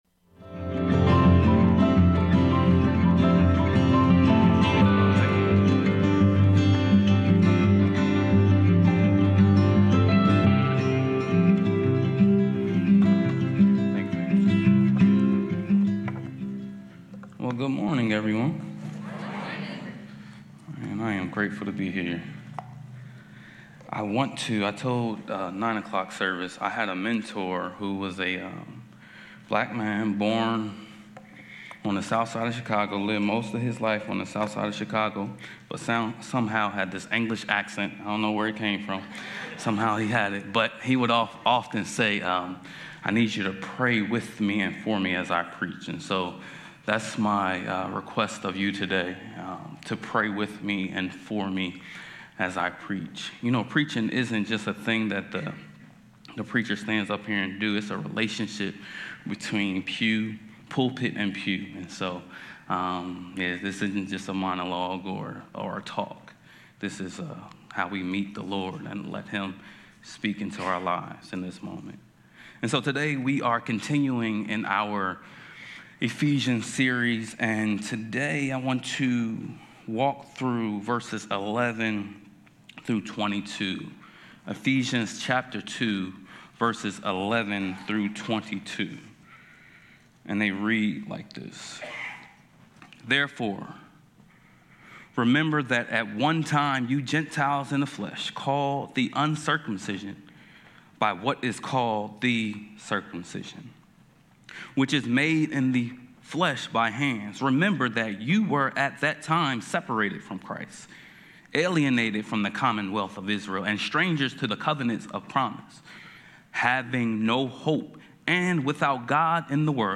A message from the series "The Book of Ephesians."